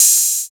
136 OP HAT.wav